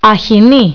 Phrase Übersetzung Phonetik Aussprache
Seeigel Αχινοί axinί